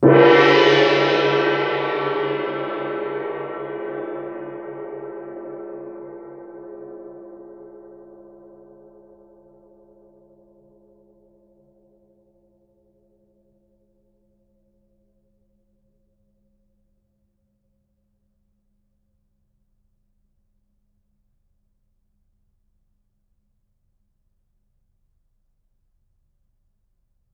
gongHit_fff.wav